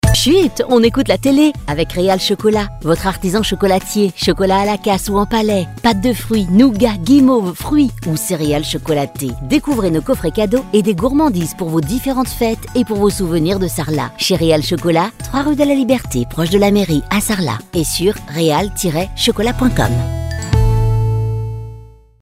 et voici le spot du magasin de Sarlat